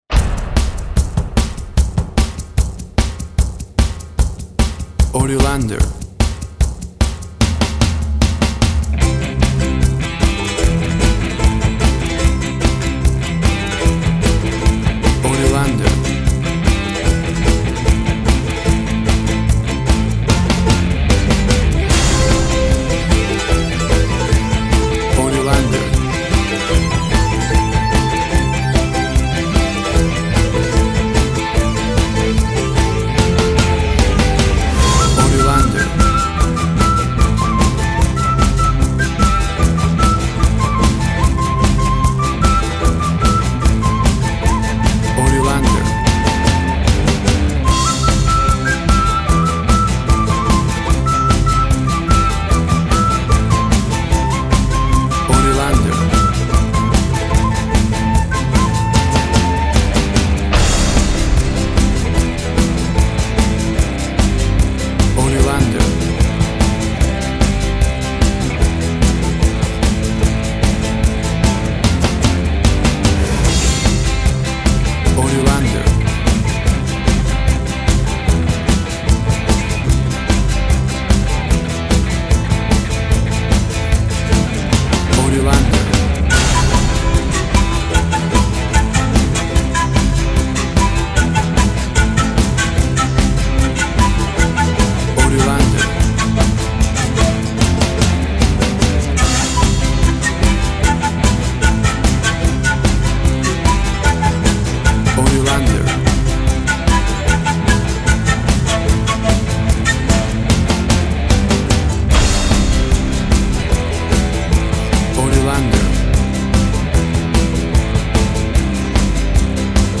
WAV Sample Rate 16-Bit Stereo, 44.1 kHz
Tempo (BPM) 140